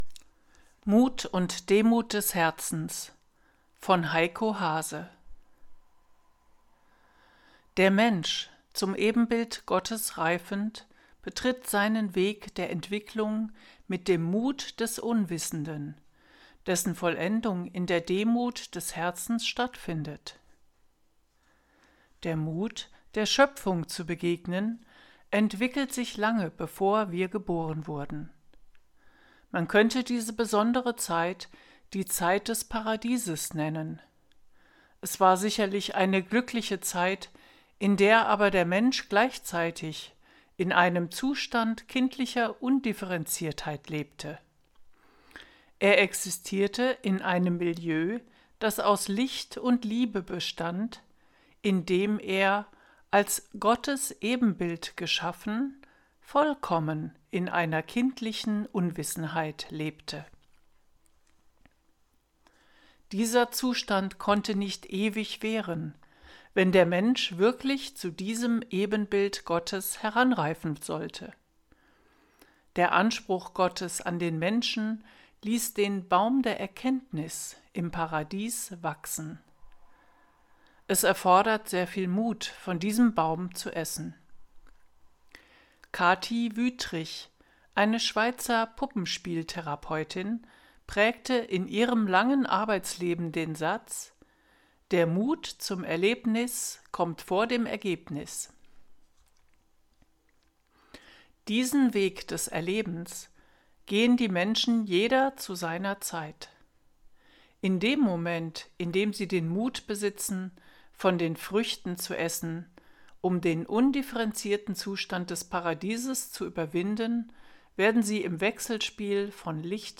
Artikel vorgelesen